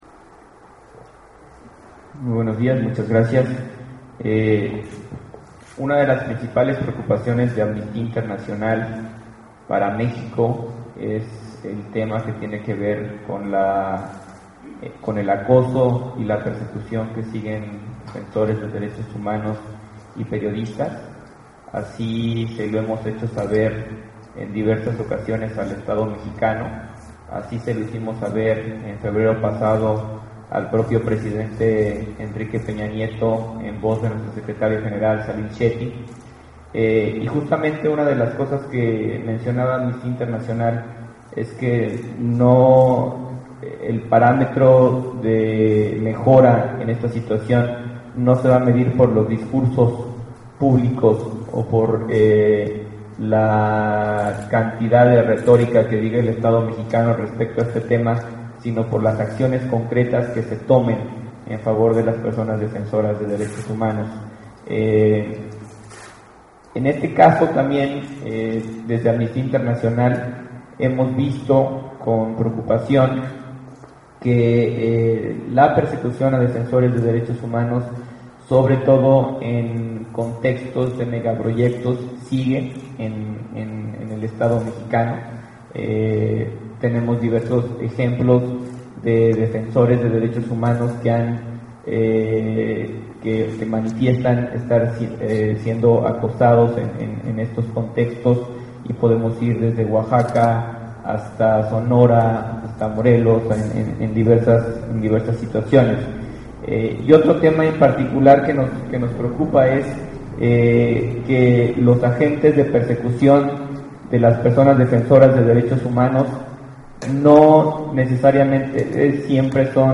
En conclusión los conferencistas concuerdan con  tres puntos:  en México las obras se realizan sin estudios previos de impacto ambiental, se desacata las sentencias de la SCJN y la nueva ley de amparo no funciona debidamente.